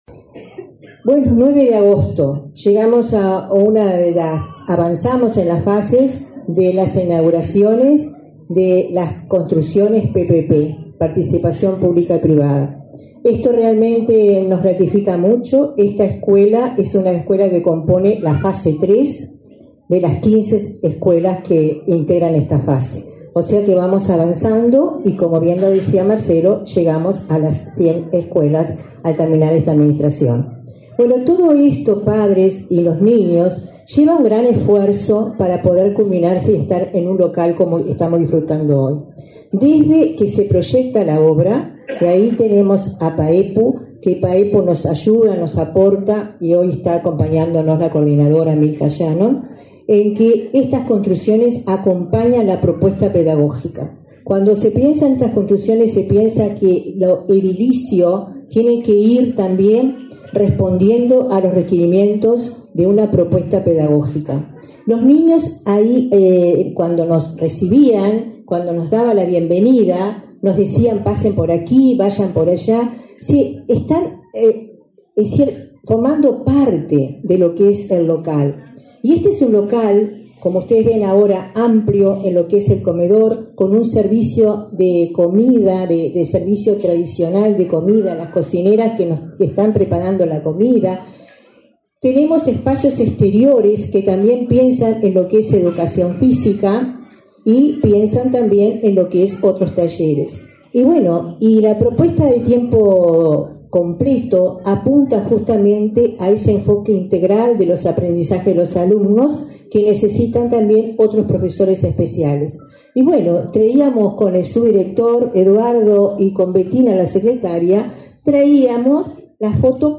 Palabras de autoridades de la ANEP en Canelones 09/08/2024 Compartir Facebook X Copiar enlace WhatsApp LinkedIn Este viernes 9, la directora general de Primaria, Olga de las Heras, y la presidenta de la Administración Nacional de Educación Pública (ANEP), Virginia Cáceres, participaron en la inauguración de la escuela n.° 320, de tiempo completo, en Parque del Plata, departamento de Canelones.